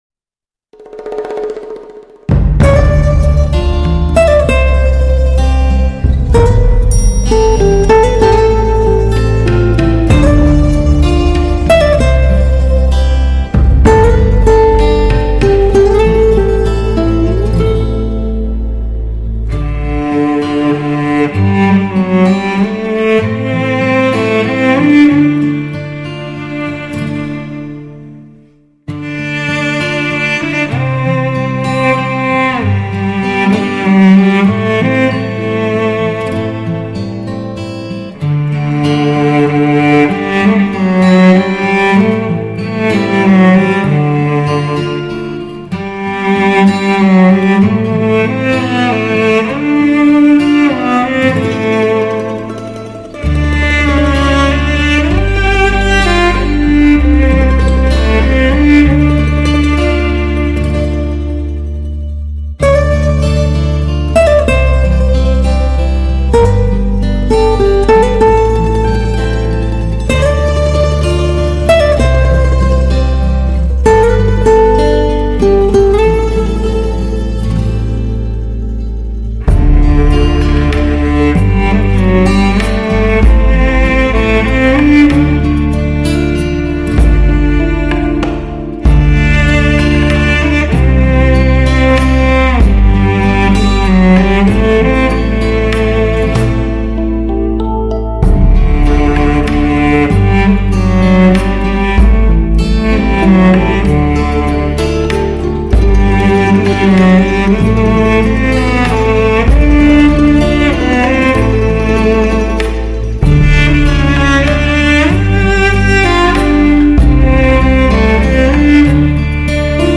大提琴细腻诉说